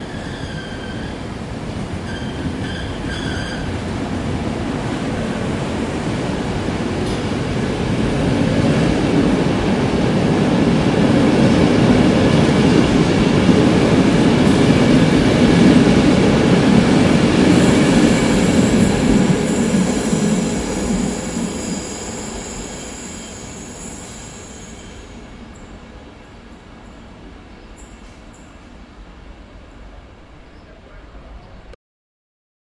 描述：记录在Harzmountains /德国的Innerste河。麦克风（AT 3031）距离河流约30米。 Oade FR2le录音机。
标签： 铁路 铁路 传球 机车 火车 火车 铁路 轨道 通过 铁路 passenger-火车 货车
声道立体声